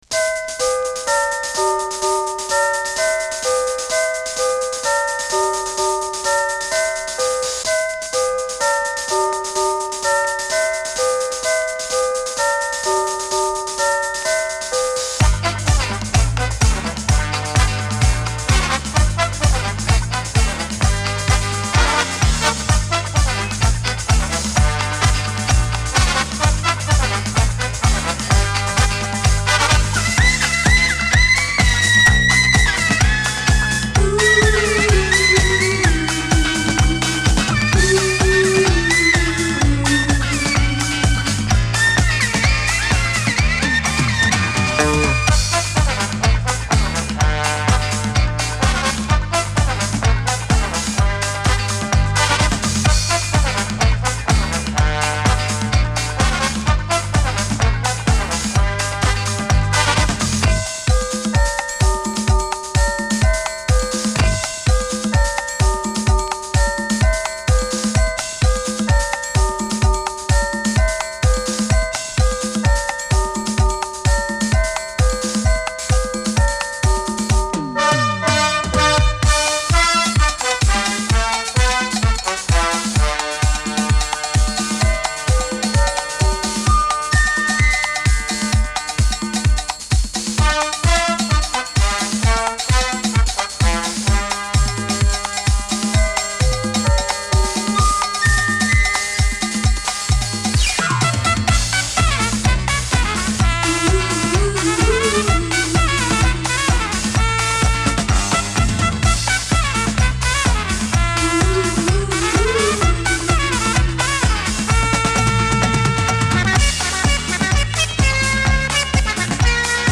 お馴染みのチャイム音、軽快なブラスもグッドです。